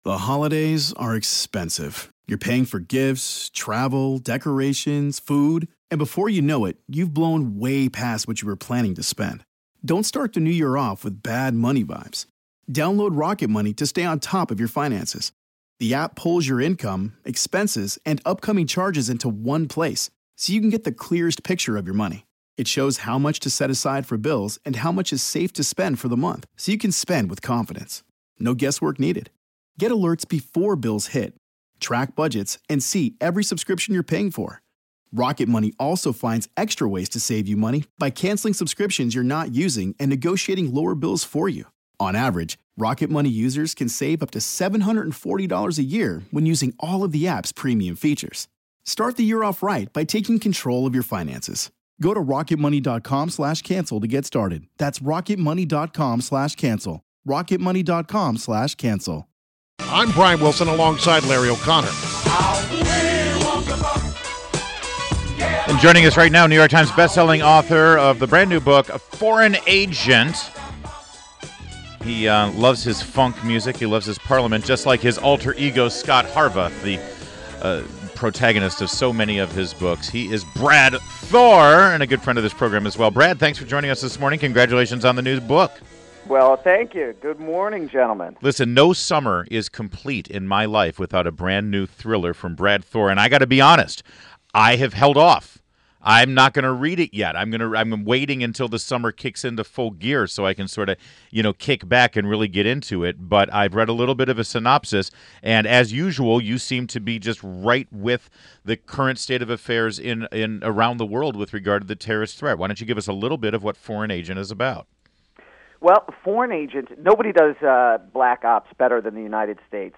WMAL Interview - BRAD THOR - 06.15.16